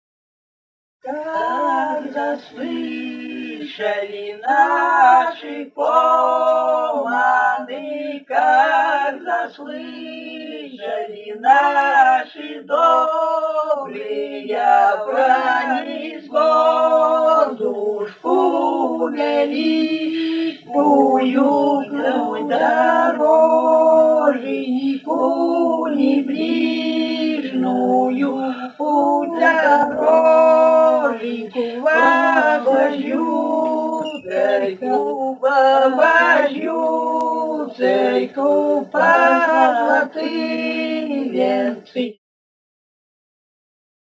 с. Урыль Катон-Карагайского р-на Восточно-Казахстанской обл.